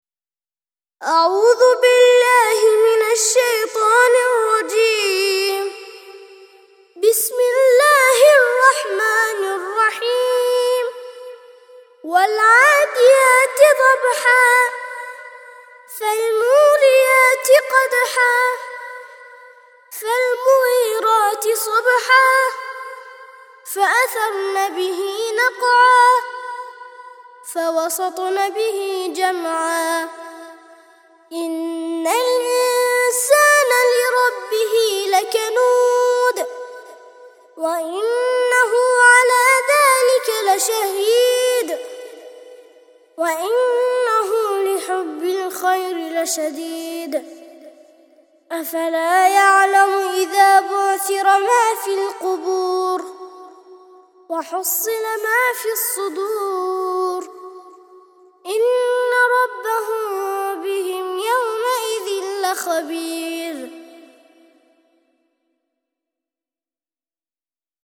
100- سورة العاديات - ترتيل سورة العاديات للأطفال لحفظ الملف في مجلد خاص اضغط بالزر الأيمن هنا ثم اختر (حفظ الهدف باسم - Save Target As) واختر المكان المناسب